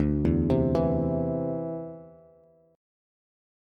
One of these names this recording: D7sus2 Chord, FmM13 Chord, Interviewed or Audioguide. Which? D7sus2 Chord